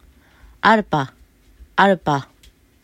アㇻパ　　　　　　arpa        行く